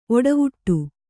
♪ oḍavuṭṭu